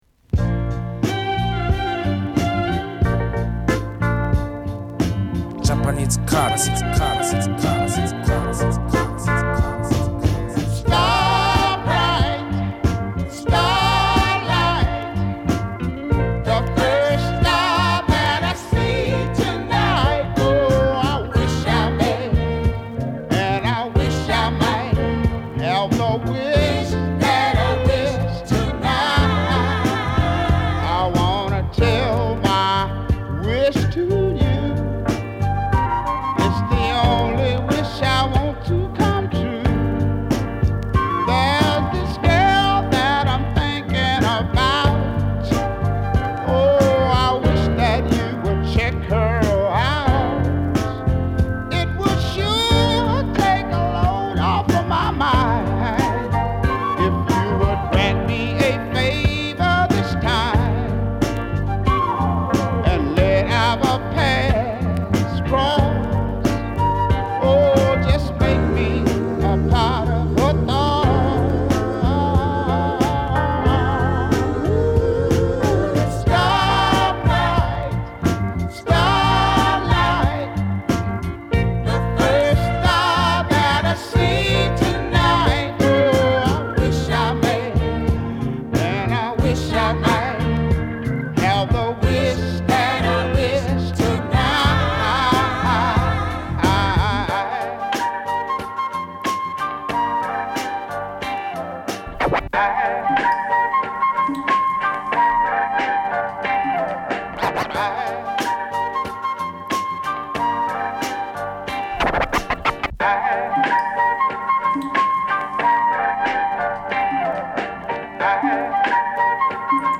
前回VOL.4がBPM早めだったのに対し今回のVOL.5はBPM遅めのDOPE&CHILLな煙たい曲を多数収録！
＊試聴はダイジェストです。